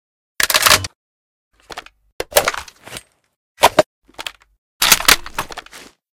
reload_empty.ogg